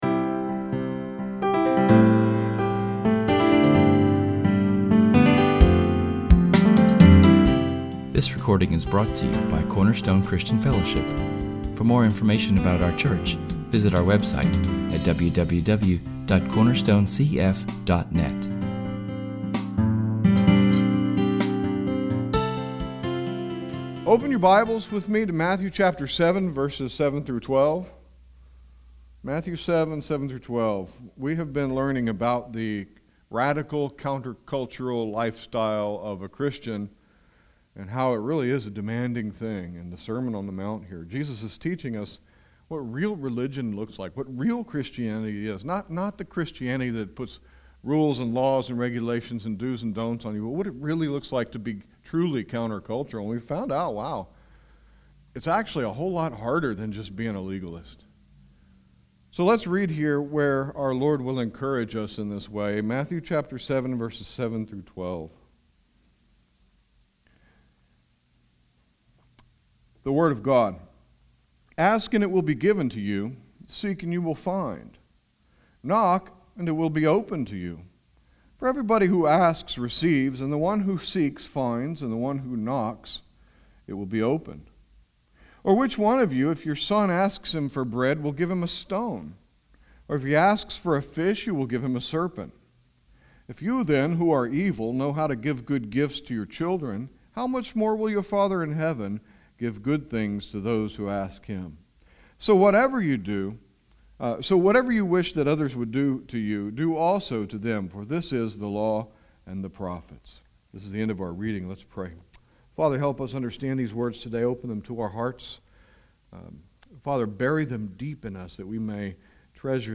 Public reading: [esvignore]James 4:1-10[/esvignore]; Benediction: [esvignore]Psalm 67:1-2[/esvignore] Matthew 7:7-12 James 4:1-10 Psalm 67:1-2